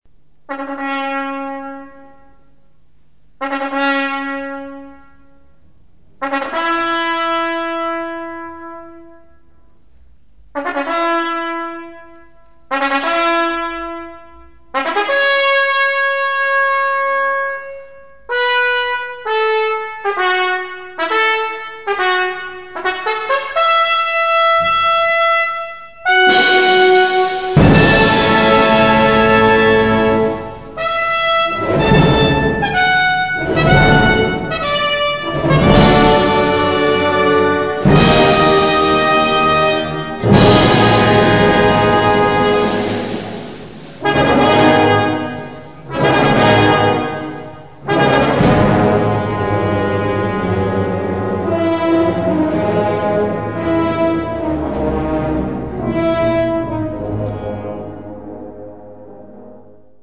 Only in the percussion did occasional restraint in the first movement betray a lack of confidence. The strings revealed fine warmth and a fair balance in the famous Adagio.